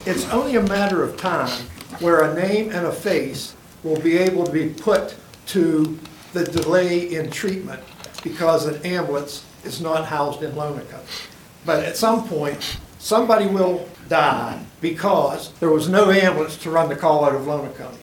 The Allegany County Commissioners heard Thursday from several area residents about the lack of ambulance service in the Georges Creek area.